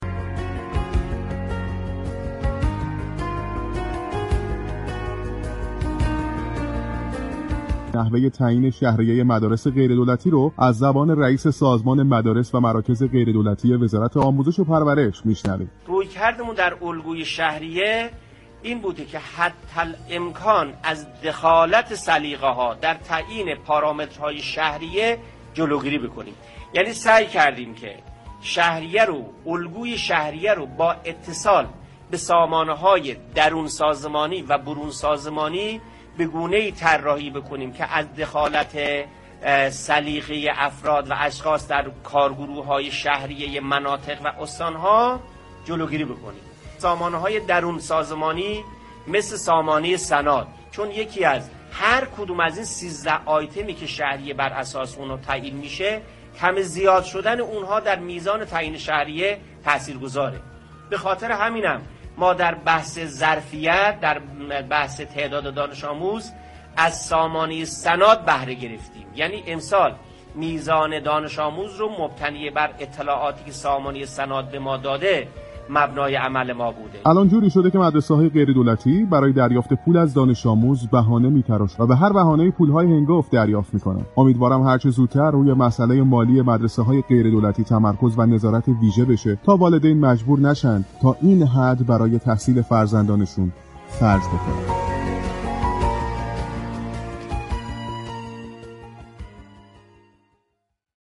مدیركل مدارس غیر‌دولتی در گفتگو با تهران‌من رادیو تهران در مورد شهریه‌های ثابت این مدارس در سطح كشور و نحوه‌ی تنطیم آن‌ها در سال تجصیلی 1399-1400 مواردی را برای مخاطبان اعلام كرد.